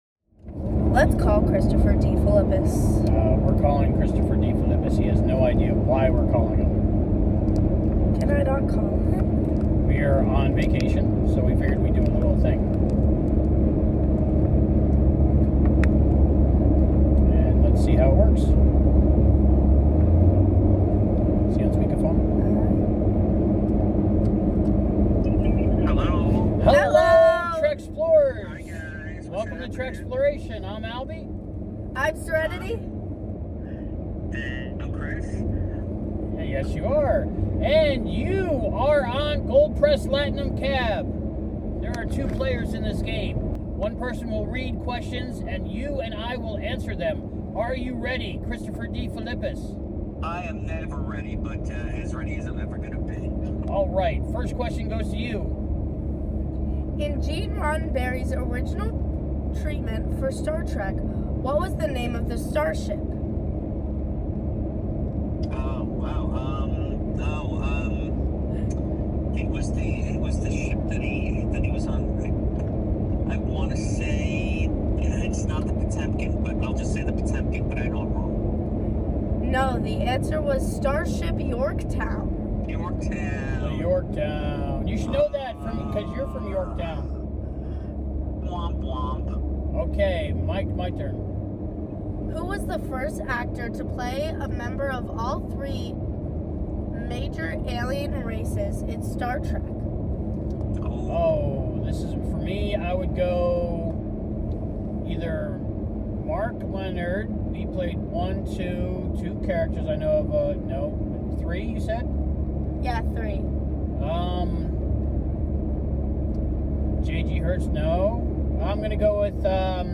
This video contains an impromptu Star Trek trivia challenge, a most valuable test of knowledge, conducted while engaging in that primitive but necessary form of transport—driving!